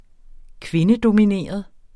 Udtale [ -domiˌneˀʌð ]